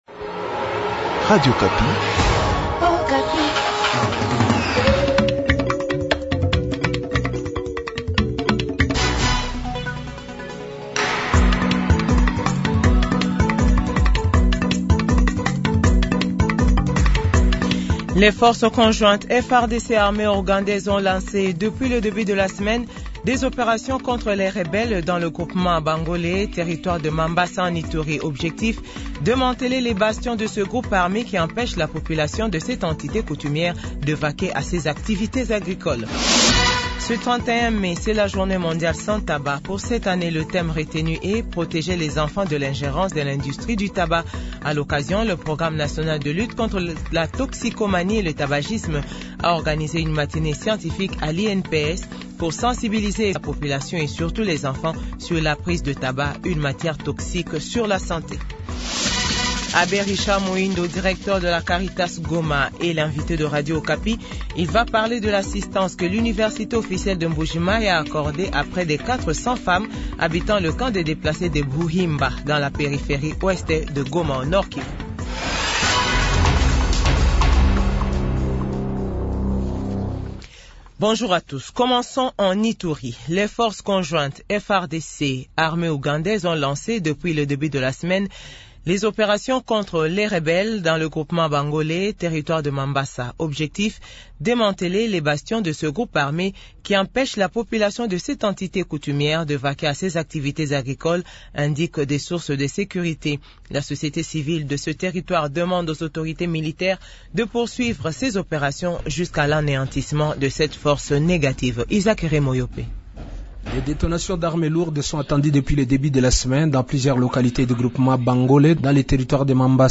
JOURNAL FRANÇAIS 12H00